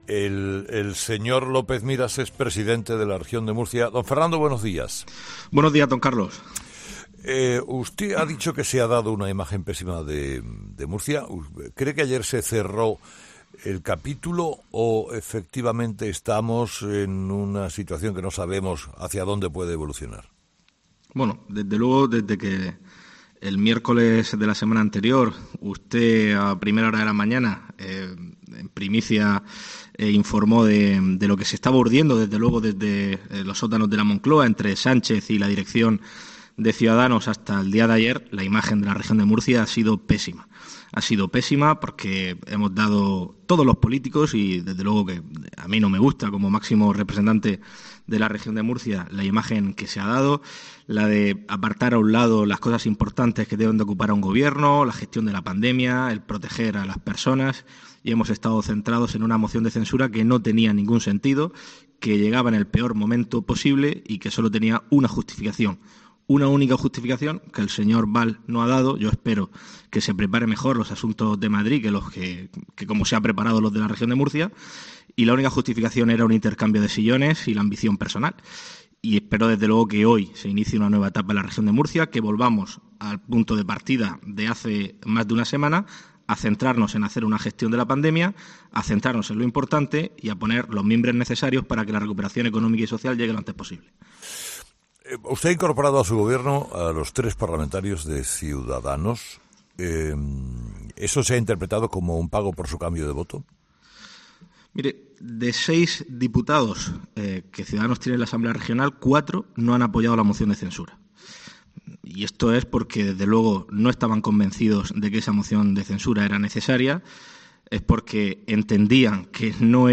El presidente de la Región de Murcia, Fernando López Miras , ha pasado este viernes por los micrófonos de Herrera en COPE tras el fracaso de la moción de censura presentada por Ciudadanos y PSOE en la comunidad.